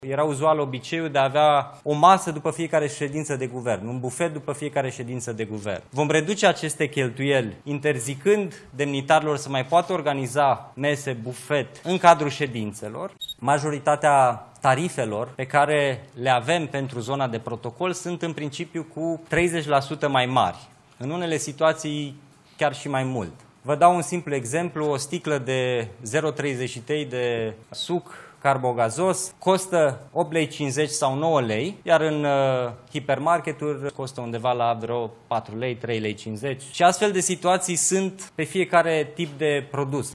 ” Era uzual obiceiul de a avea o masă după fiecare ședință de Guvern, un bufet după fiecare ședință de Guvern. Vom reduce aceste cheltuieli, interzicând demnitarilor să mai poată organiza mese, bufet” a spus Mihai Jurca.